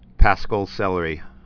(păskəl)